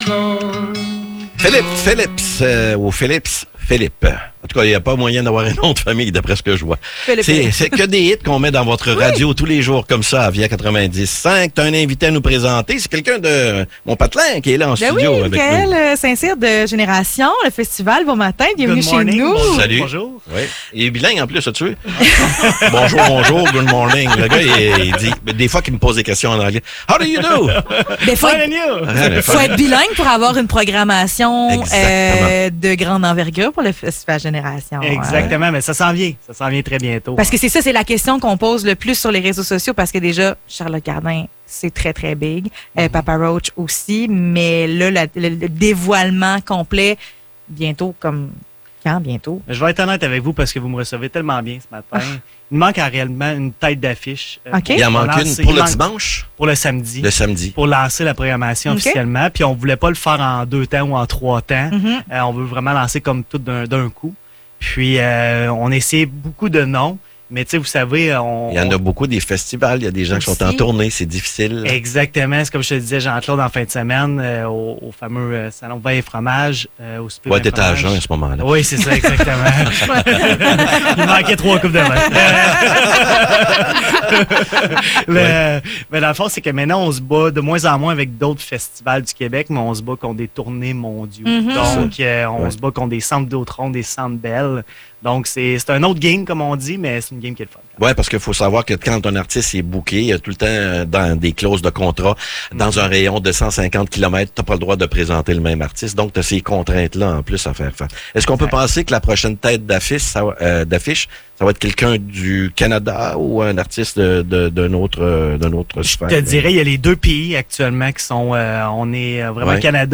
Entrevue pour le festival Générations